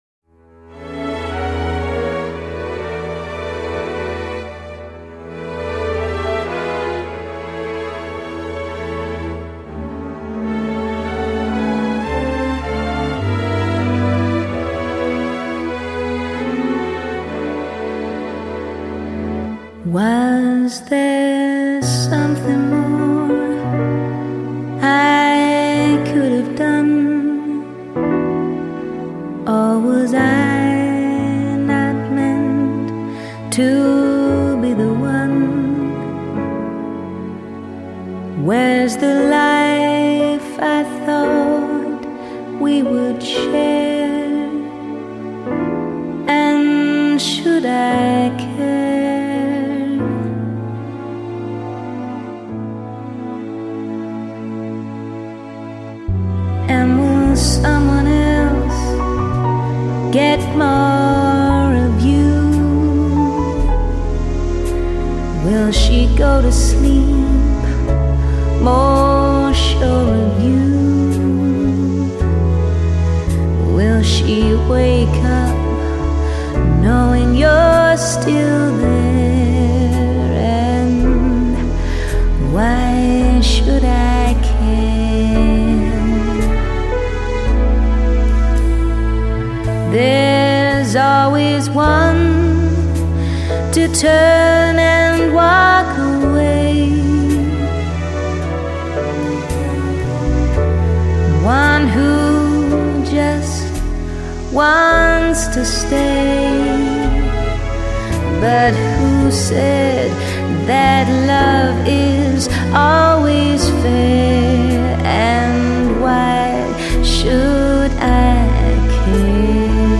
如同一张有魔力咒语的网，声音如同丝一般轻薄的漂浮在流行艺术与现代古典之间